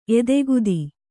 ♪ edegudi